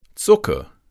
Begriffe von Hochdeutsch auf Platt und umgekehrt übersetzen, plattdeutsche Tonbeispiele, Schreibregeln und Suchfunktionen zu regelmäßigen und unregelmäßigen Verben.
Hochdeutsch Plattdeutsch direkte Treffer Substantive der Zucker de Zucker (das Z wird häufig wie ein scharfes S gespr.)